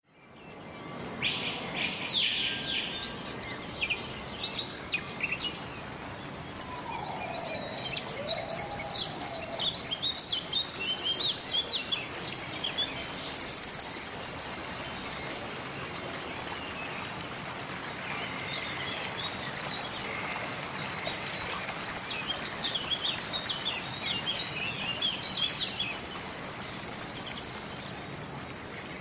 Category Animal